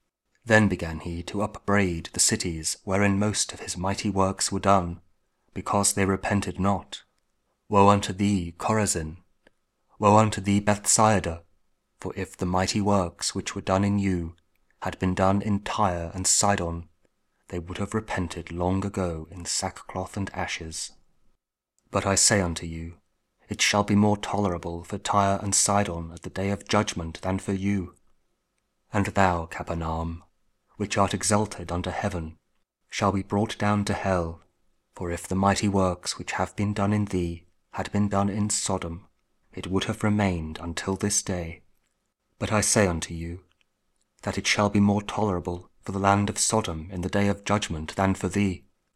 Matthew 11: 20-24 – Week 15 Ordinary Time, Tuesday (King James Audio Bible KJV, Spoken Word)